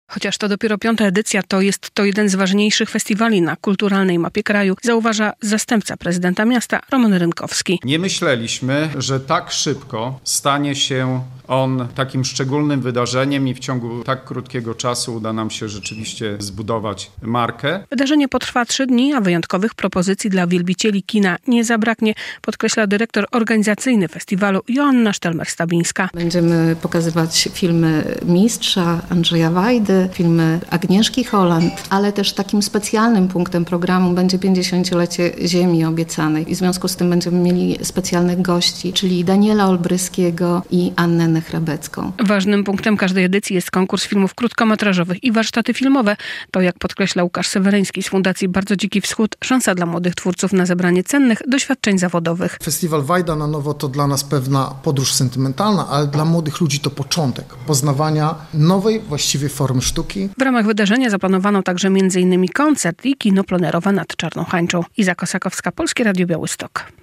relacja
Podczas konferencji prasowej w Suwalskim Ośrodku Kultury, organizatorzy Festiwalu Filmowego "Wajda na Nowo" przedstawili (13.06) szczegółowy plan festiwalu.